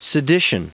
Prononciation du mot sedition en anglais (fichier audio)
Prononciation du mot : sedition